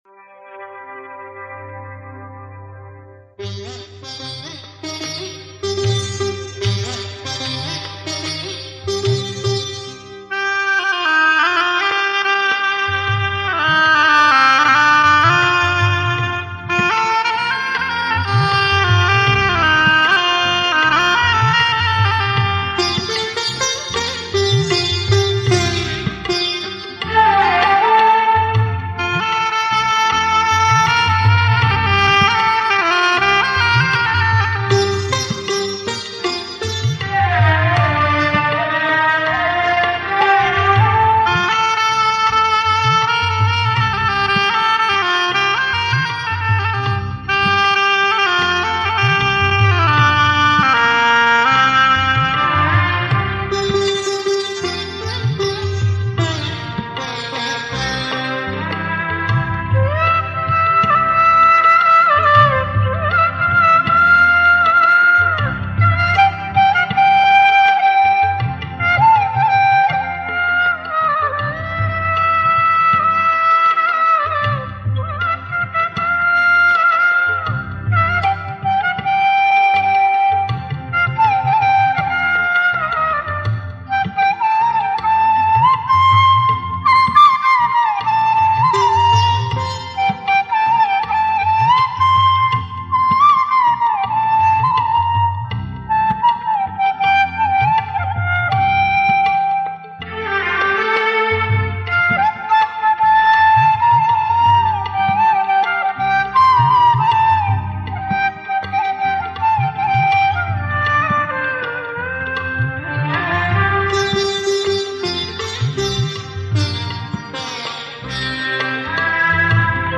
Sinhala Instrumental Mp3 Download